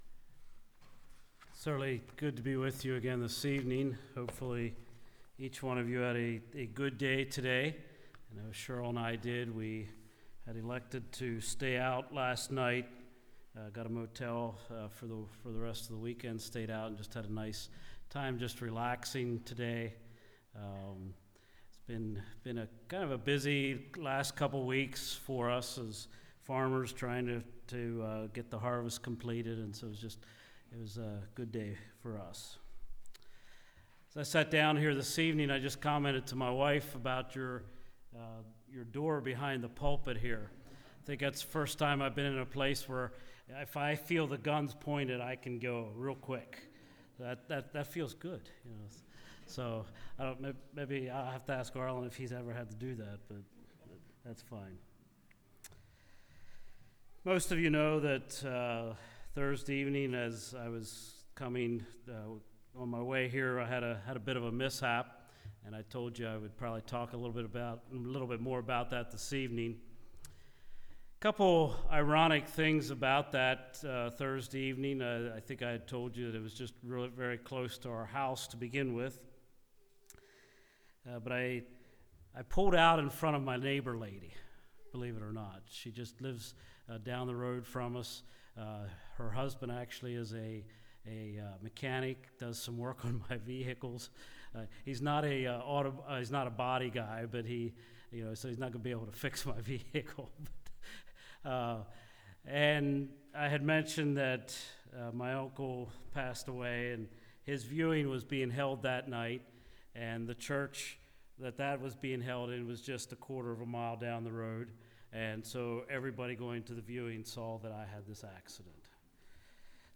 Service Type: Revivals